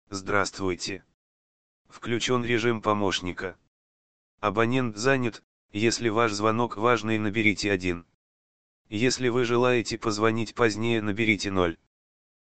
Абонент занят_IP
Абонент-занят_IP.mp3